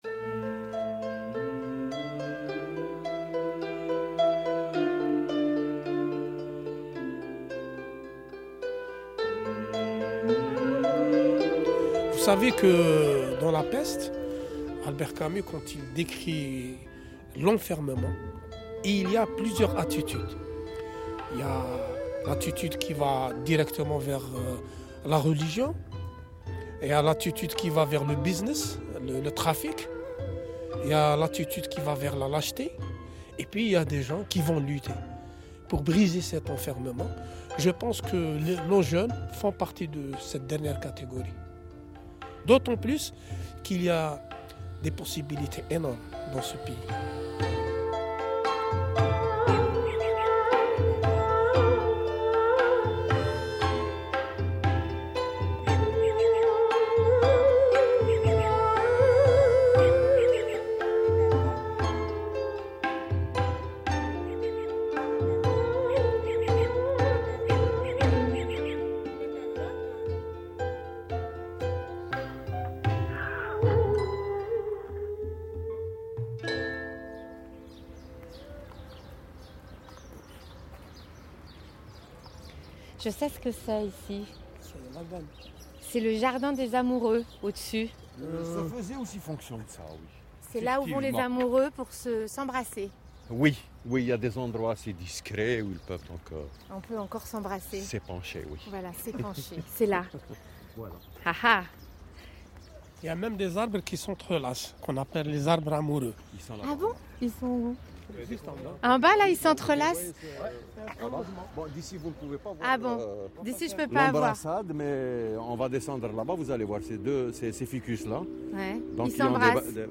C’est terrible, mais après huit mois, ce qui me reste, c’est la musique du générique…
…d’une mélancolie incroyable.
J’ai mis les 5 premières minutes de l’émission.
Mais pas sans avoir écouté une dernière fois cette musique et les rires des femmes.